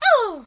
peach_attacked.ogg